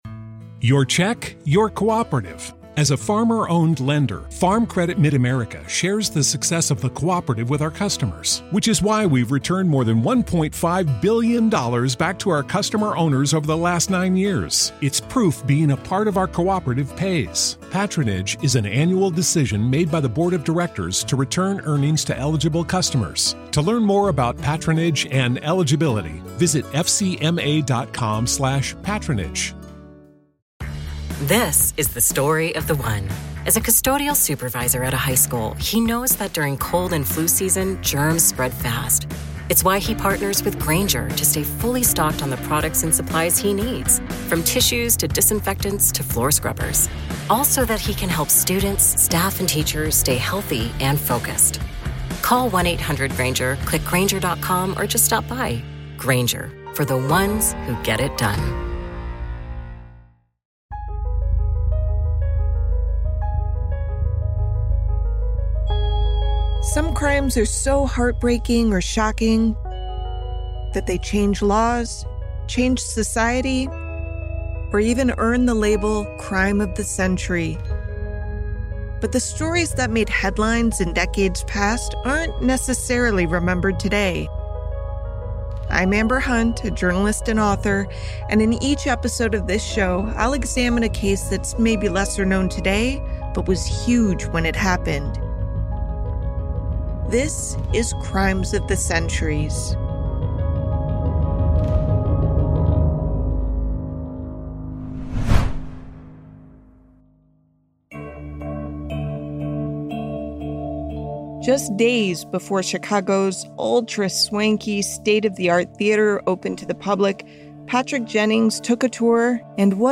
" Crimes of the Centuries " is a podcast from Grab Bag Collab exploring forgotten crimes from times past that made a mark and helped change history.